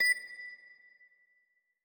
bell.wav